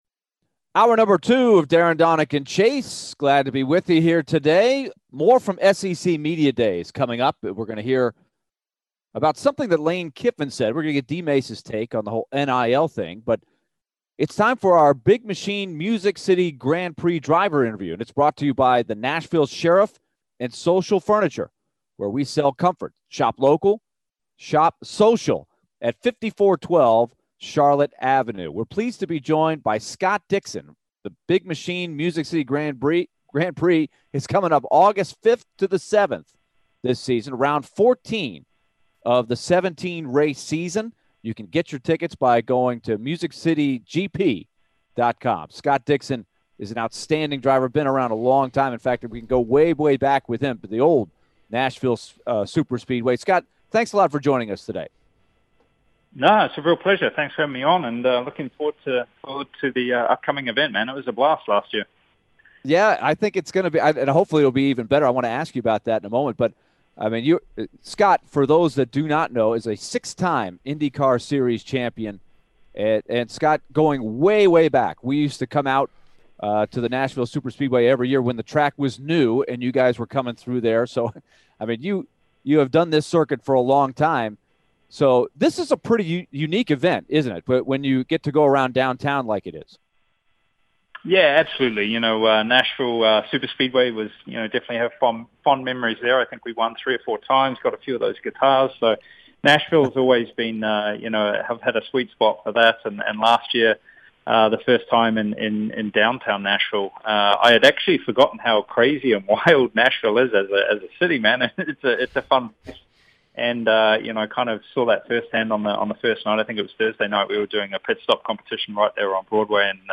Scott Dixon Full Interview (07-19-22)